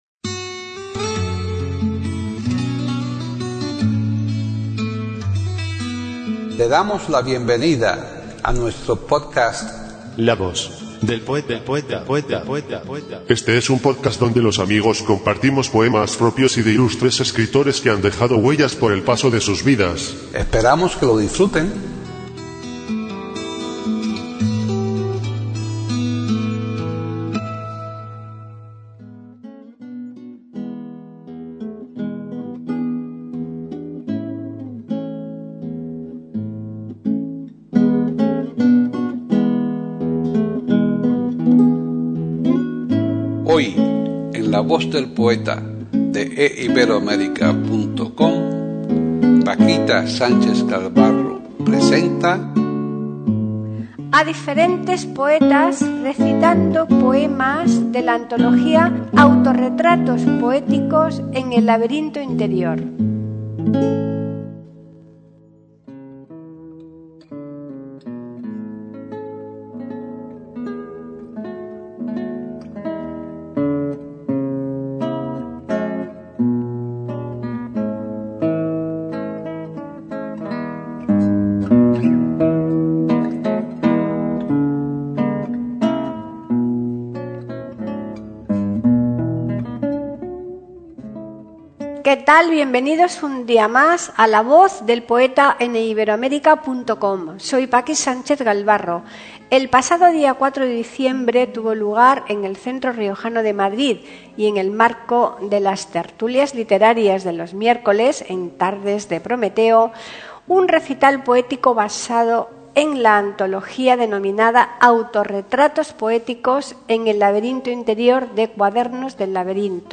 0730_RecitalSobreLaAntologiaAutorretratosPoeticosEnElLaberintoInterior.mp3